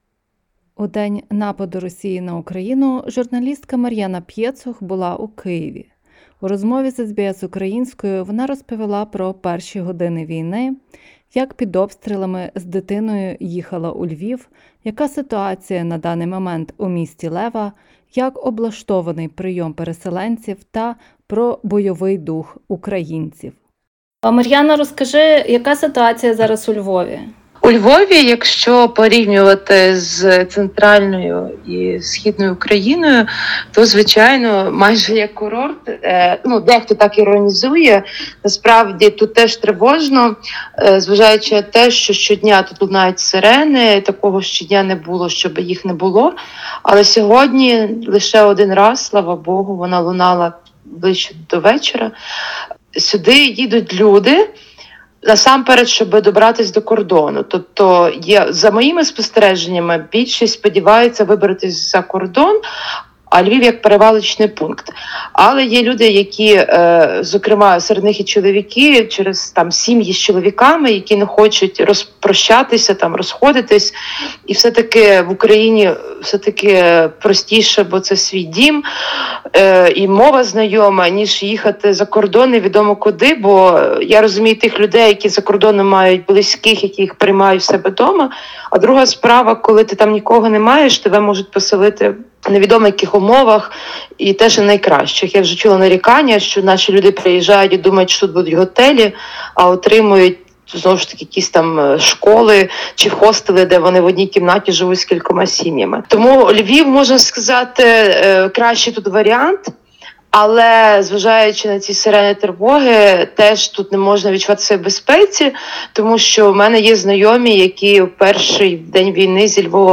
Розмова з львів’янкою про евакуацію з Києва, переселенців та бойовий дух українців.